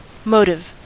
Ääntäminen
US : IPA : /ˈmoʊtɪv/